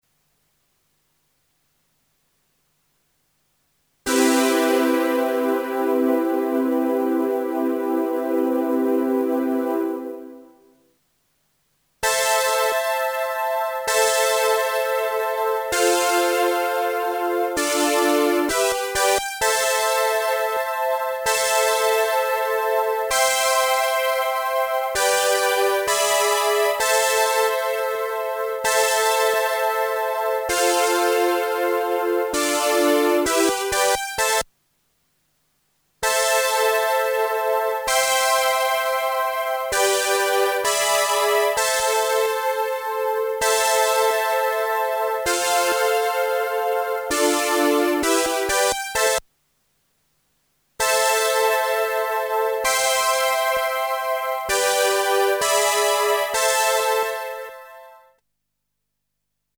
In dem Beispiel habe ich ein Preset geladen (D-400 Bit Poly 2).
In der Aufnahme halte ich zum Vergleich zunächst mit den Pads der 707 4 Noten. Da ist noch alles wie es soll. Dann starte ich den Sequenzer, der 4-Noten Flächen-Akkorde spielt.